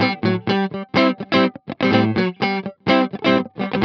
23 GuitarFunky Loop A.wav